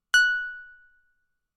Roland Juno 6 PW Pluck " Roland Juno 6 PW Pluck F6 (PW Pluck90127)
标签： F6 MIDI音符-90 罗兰朱诺-6 合成器 单票据 多重采样
声道立体声